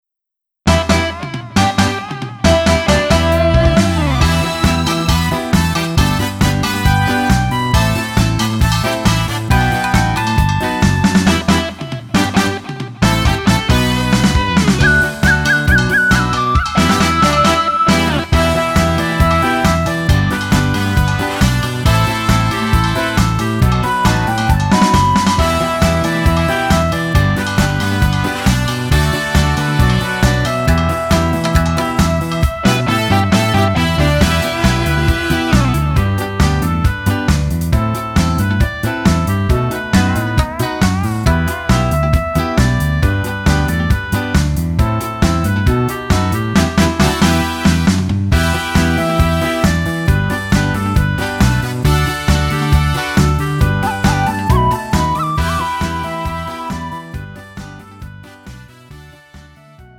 음정 -1키 3:17
장르 가요 구분 Pro MR
Pro MR은 공연, 축가, 전문 커버 등에 적합한 고음질 반주입니다.